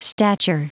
Prononciation du mot stature en anglais (fichier audio)
Prononciation du mot : stature